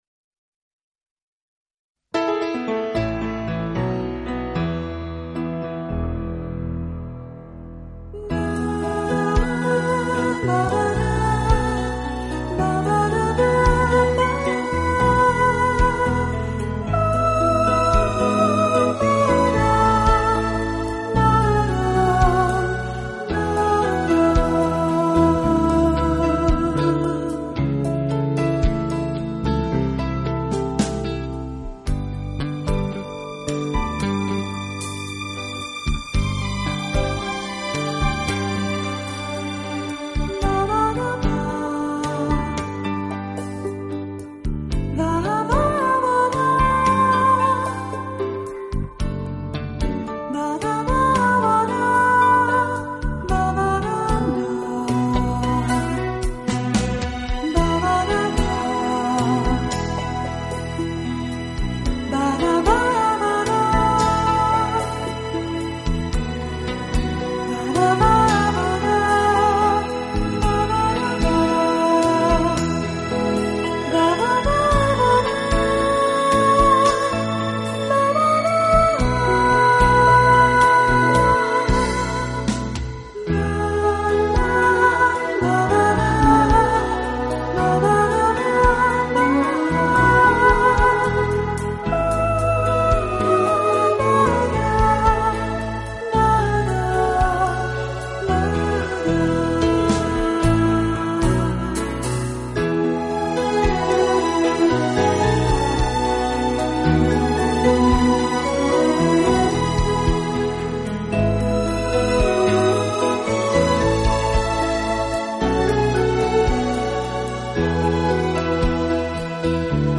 【顶级轻音乐】
Genre: Instrumental / Easy Listening